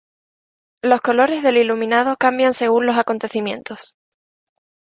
i‧lu‧mi‧na‧do
Pronunciado como (IPA)
/ilumiˈnado/